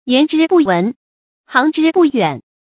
yán zhī bù wén，xíng zhī bù yuǎn
言之不文，行之不远发音